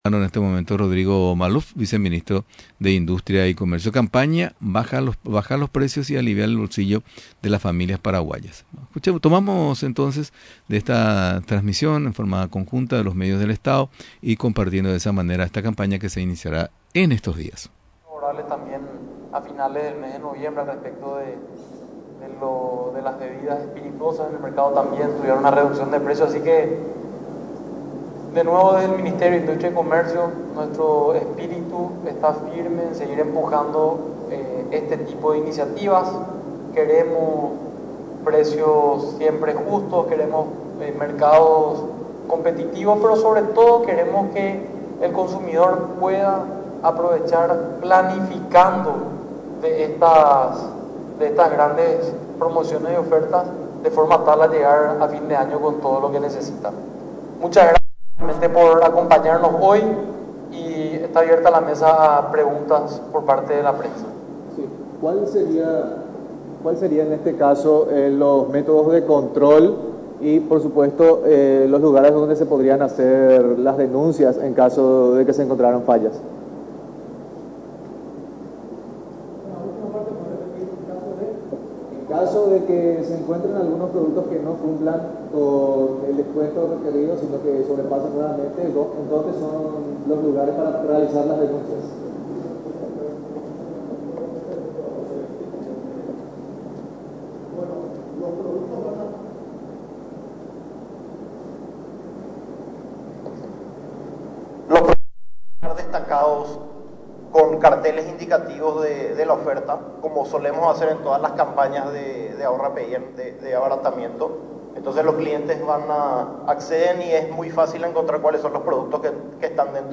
La campaña que baja los precios y alivia el bolsillo de las familias paraguayas, se iniciará desde el 5 al 19 de diciembre, destacó este lunes en rueda de prensa, el viceministro de Industria y Comercio, Rodrigo Maluff.